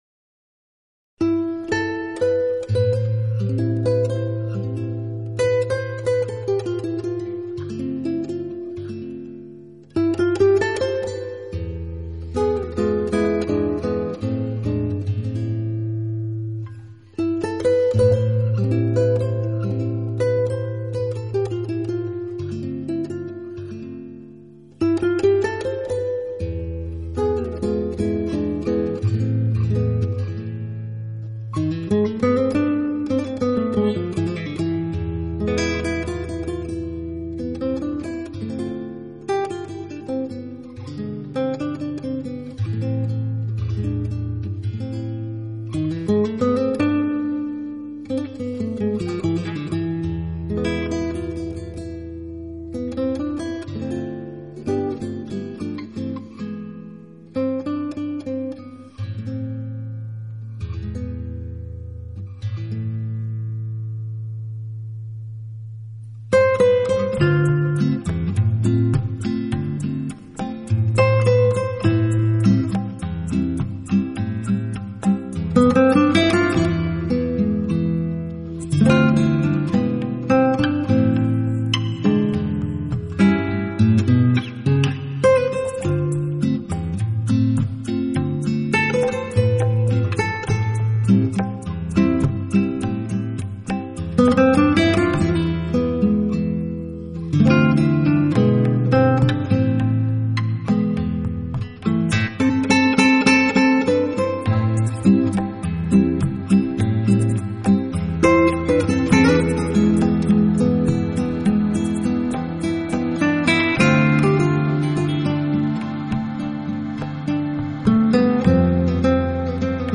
风格: Nu-Soul，Contemporary Jazz，World Music，Latin
张CD为其推荐2006年度音乐精选，多带有Latin Jazz色彩，不少曲目非常入耳。